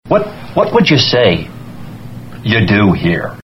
Tags: sports radio